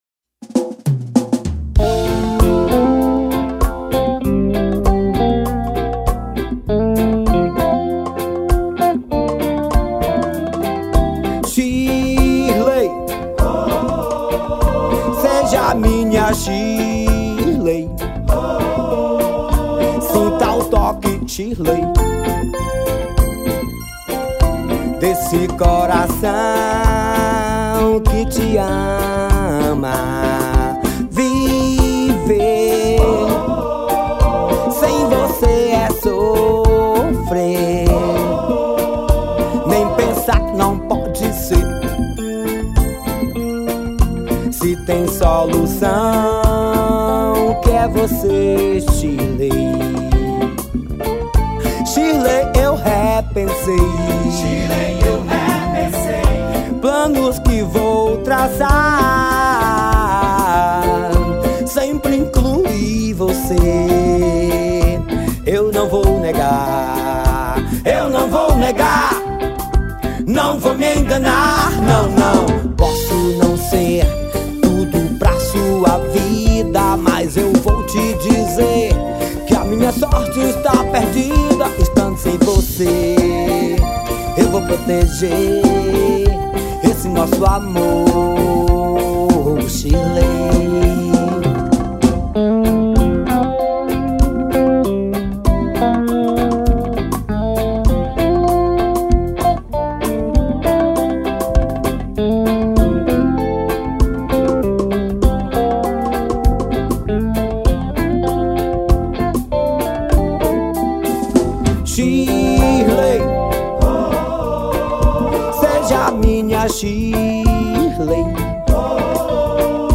2241   03:00:00   Faixa:     Forró